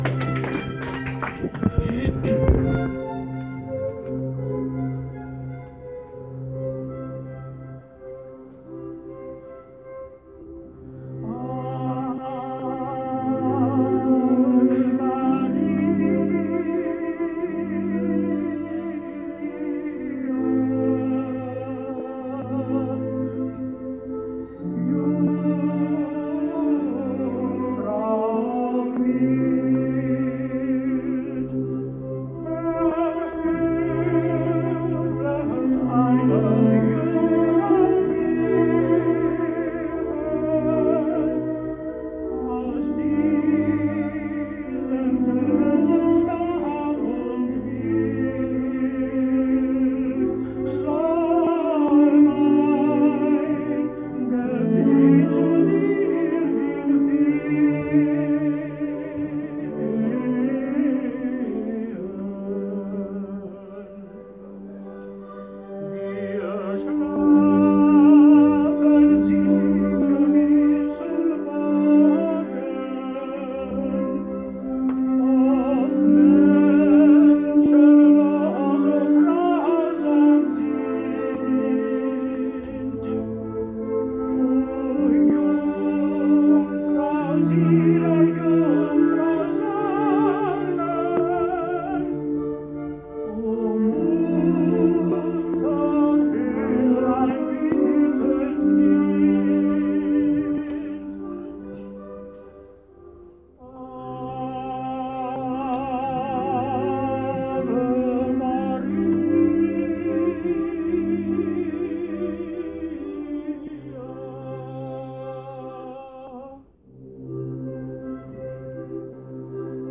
Dezember Weihnachtsfeier im Berggasthaus
Mit viel Beifall wurde eine bewegende Liedfolge,